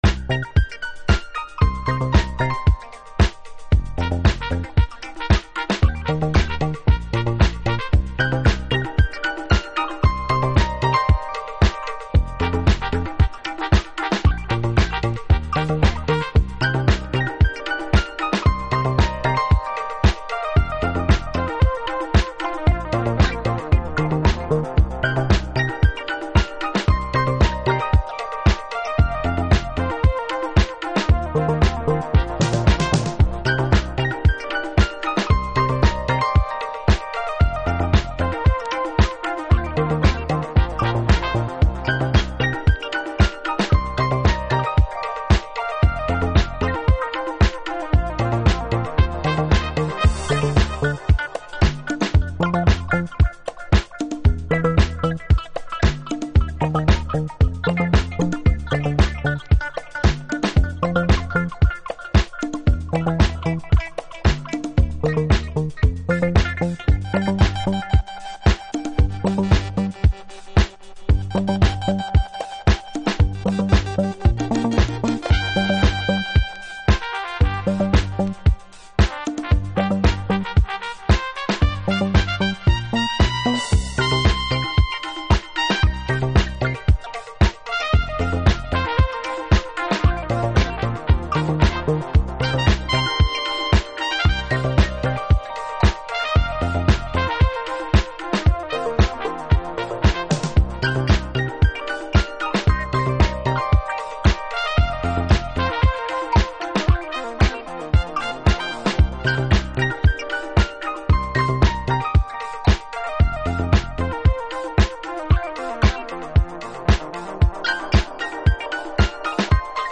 Alt Disco / Boogie
ダンスフロアへの愛情と独自の文脈が生み出すハウスとディスコの狭間、タイトルが示すようなメタフォリカルに蠢く揺らぎ。